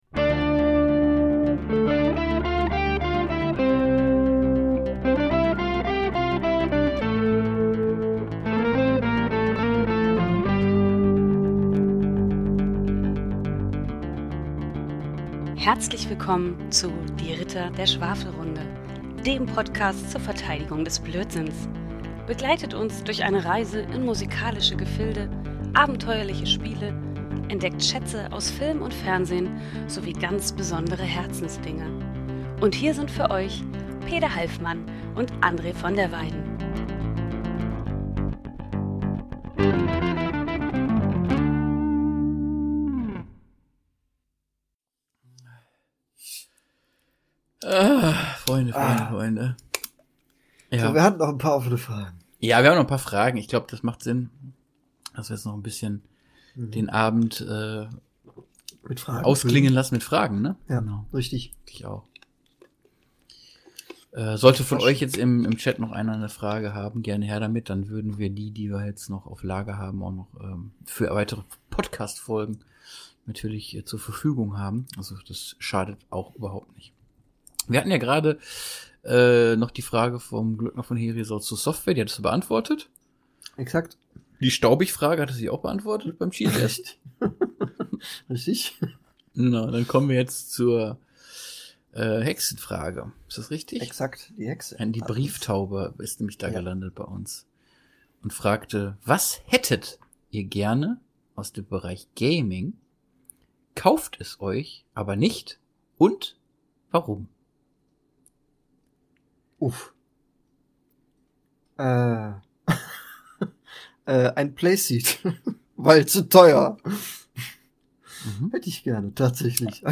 Hier ist er endlich: der dritte und letzte Teil unserer großen Live-Verschwafelung vom 06.07. In dieser finalen halben Folge beantworten wir noch ein paar Fragen aus der Community und feiern das XXL-Format, das wir an einem herrlich verquatschten Sonntag aufzeichnen durften.